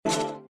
pause-continue-click.ogg